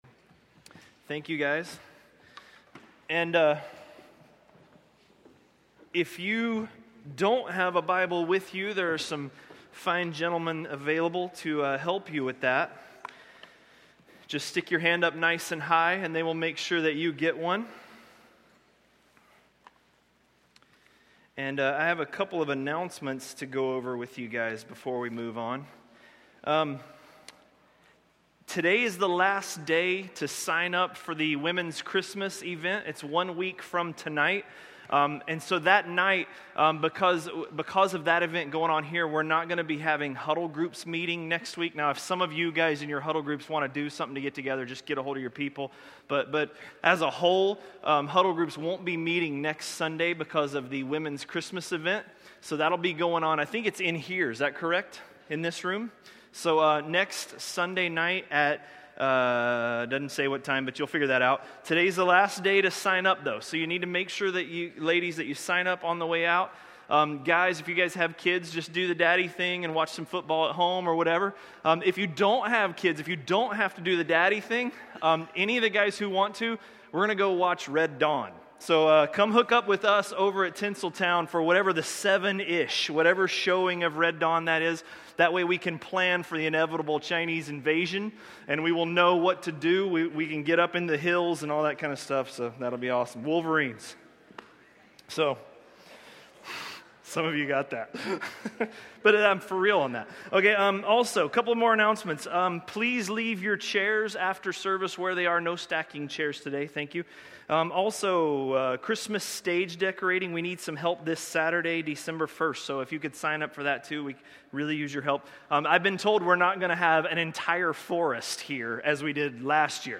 Message
A message from the series "Romans."